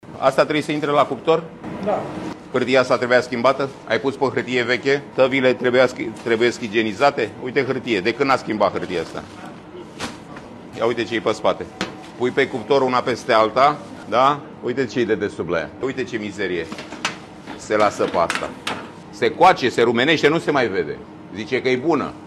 Preşedintele Autorităţii Naţionale pentru Protecţia Consumatorilor Cristian Popescu Piedone.